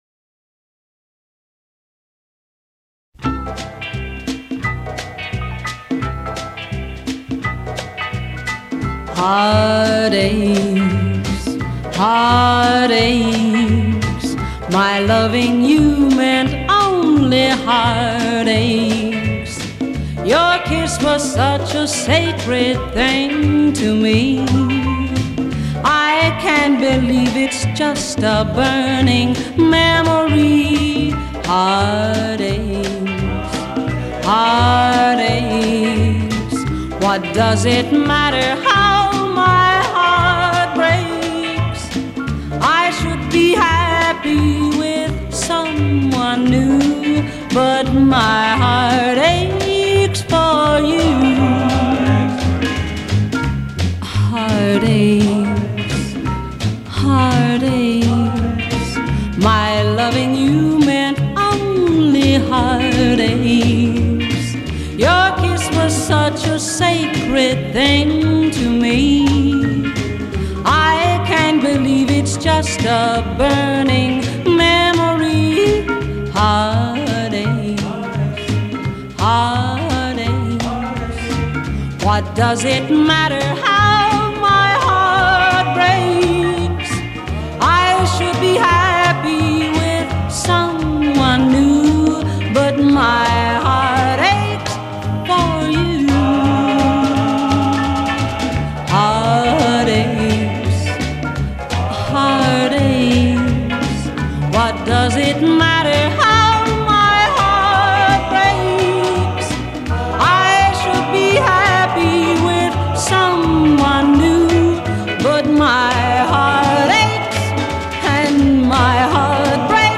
прекрасной певицей
и её весьма приятными и мелодичными композициями.